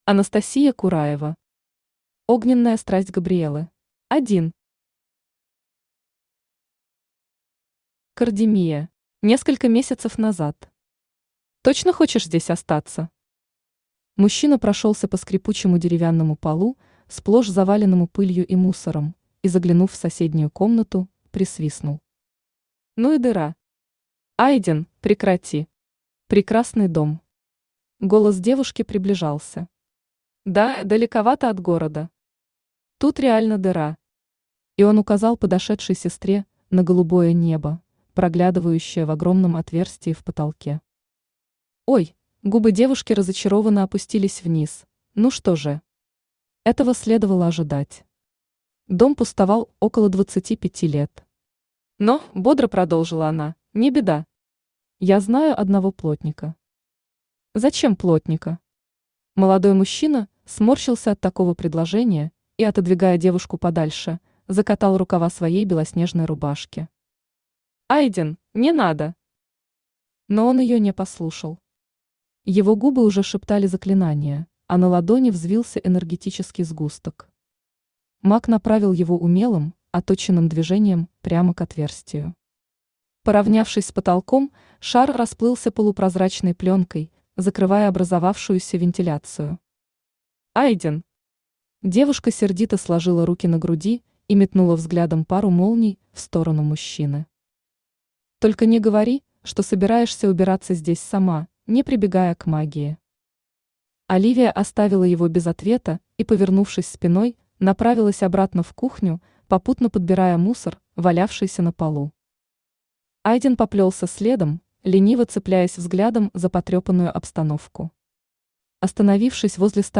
Аудиокнига Огненная страсть Габриэллы | Библиотека аудиокниг
Aудиокнига Огненная страсть Габриэллы Автор Анастасия Кураева Читает аудиокнигу Авточтец ЛитРес.